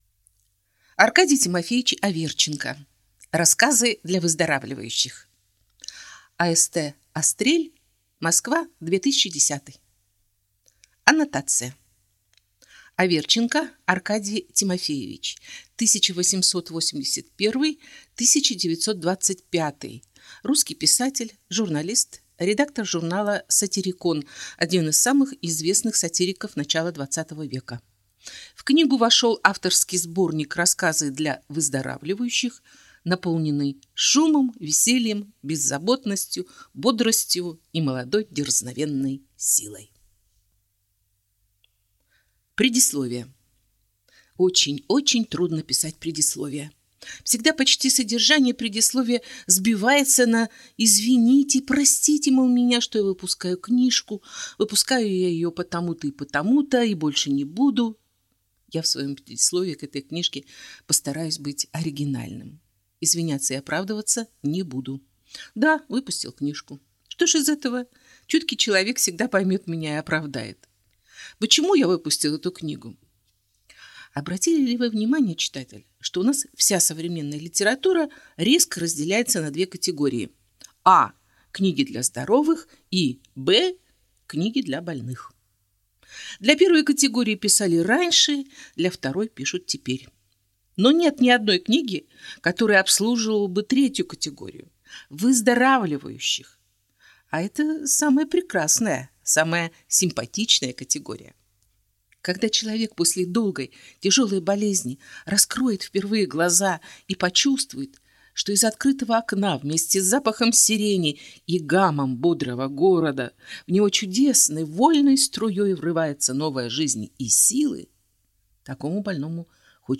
Аудиокнига Рассказы для выздоравливающих | Библиотека аудиокниг